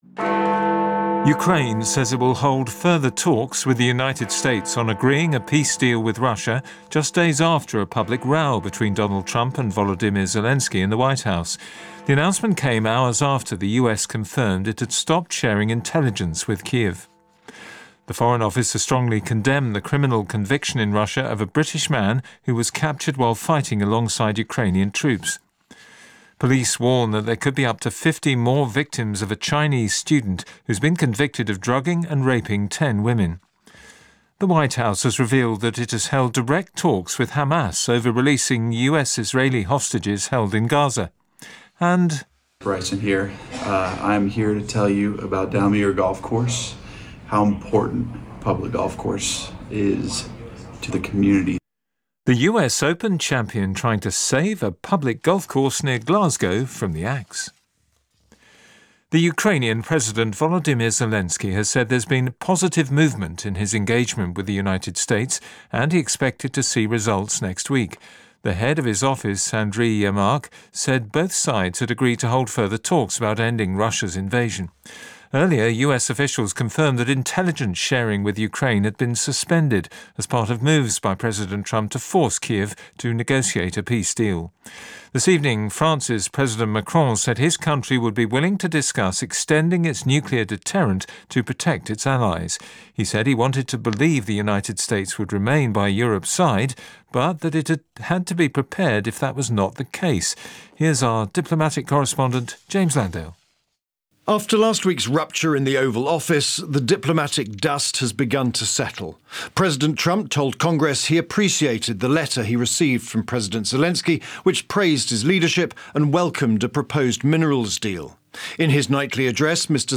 National and international news from BBC Radio 4